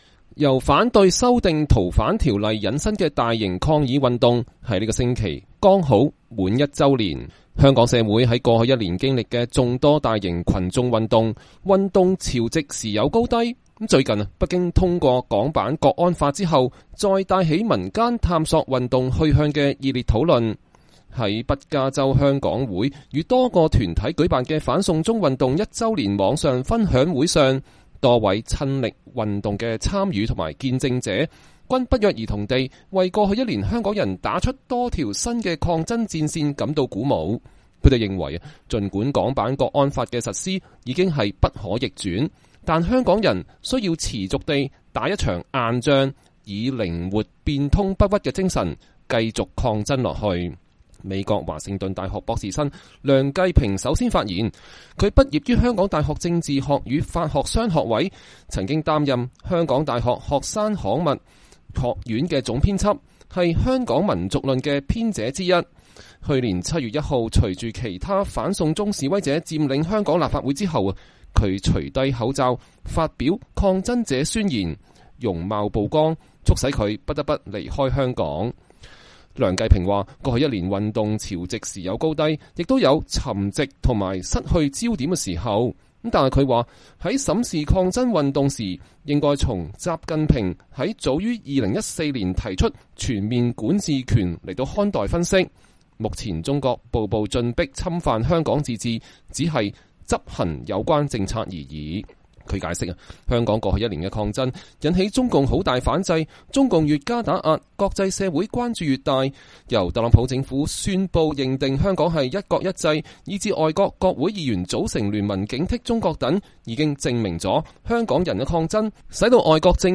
在“北加州香港會”與多個團體舉辦的反送中運動一週年網上分享會上，多位親歷運動的參與及見證者，均不約而同地為過去一年香港人打出了多條新的抗爭戰線感到鼓舞。